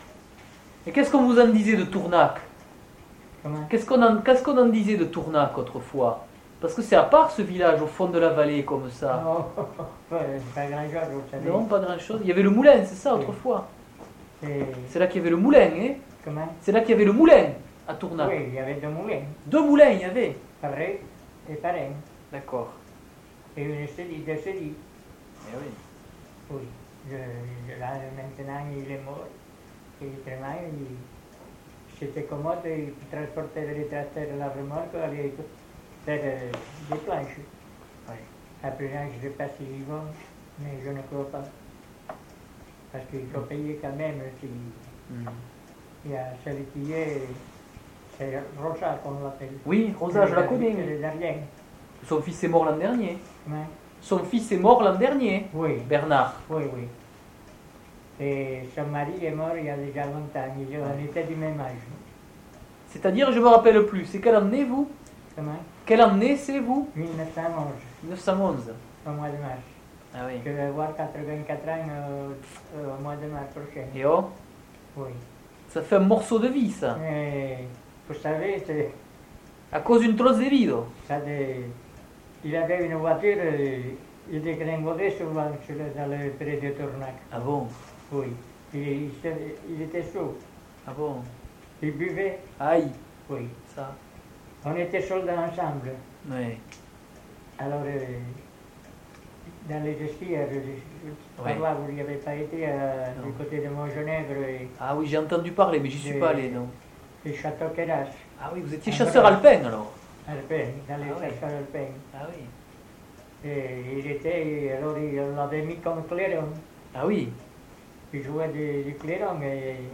Lieu : Samortein (lieu-dit)
Genre : témoignage thématique